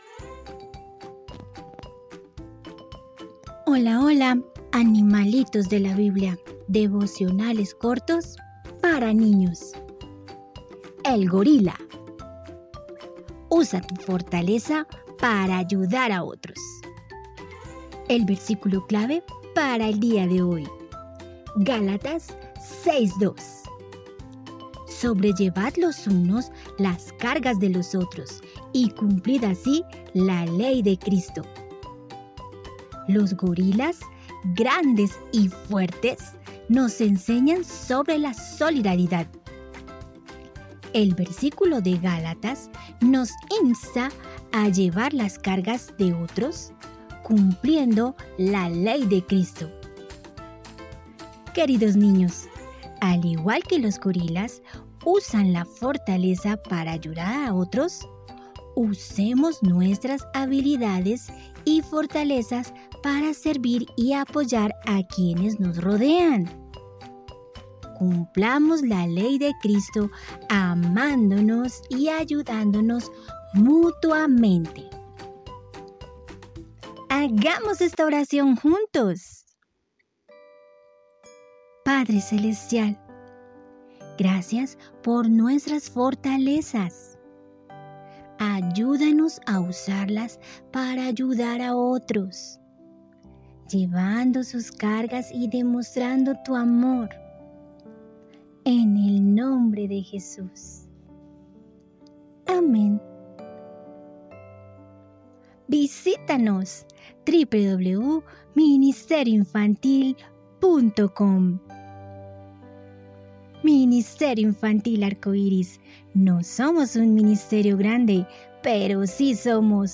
Animalitos de la Biblia – Devocionales Cortos para Niños